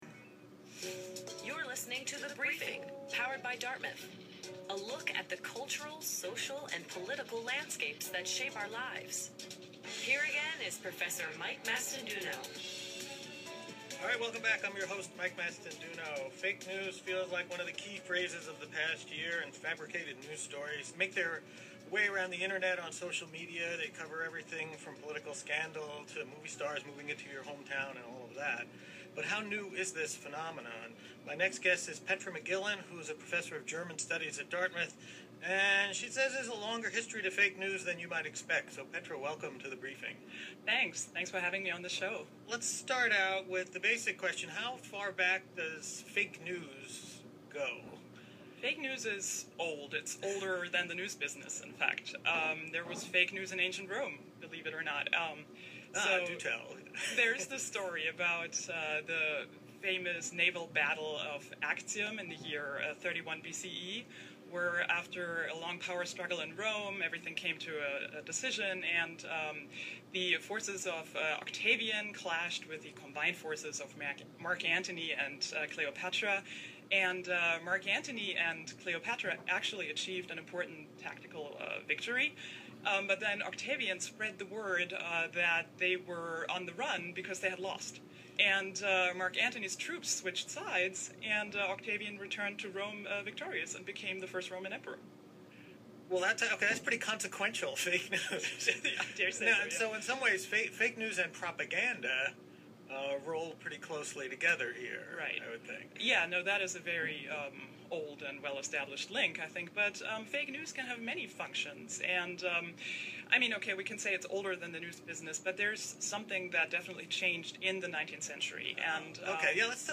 radio-interview-the-briefing.mp3